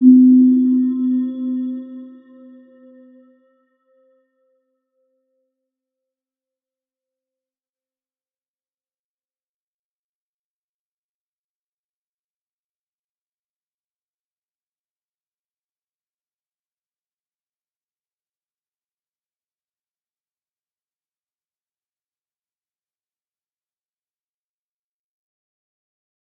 Round-Bell-C4-mf.wav